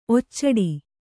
♪ occaḍi